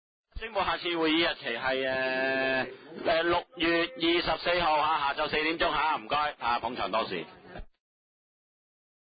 地區工程及設施管理委員會第三次會議
灣仔民政事務處區議會會議室